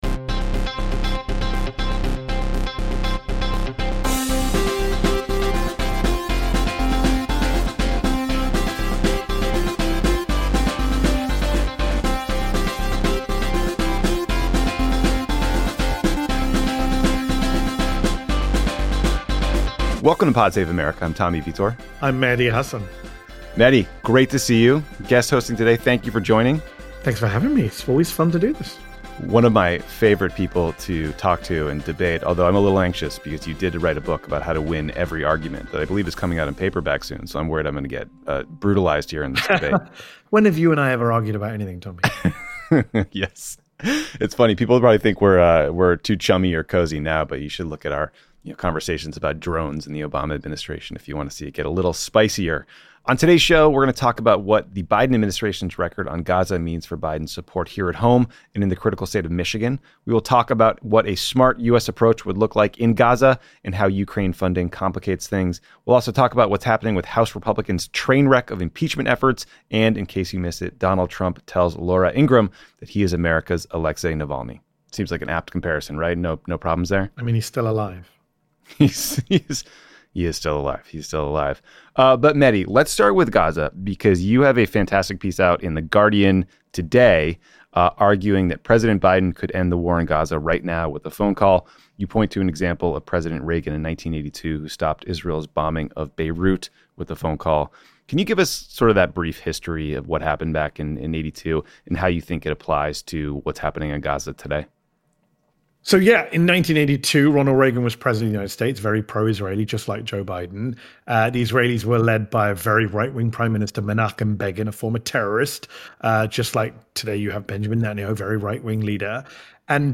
Tommy and guest host Mehdi Hasan break down the Biden administration's record on Gaza, what should happen next, and how the ongoing violence could hurt Biden in Michigan and beyond. Plus, the latest on House Republicans' impeachment trainwreck, Donald Trump co-opting Alexei Navalny's legacy, and Tucker Carlson's softball interview with Vladimir Putin.